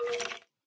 minecraft / sounds / mob / skeleton / say1.ogg